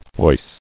[foist]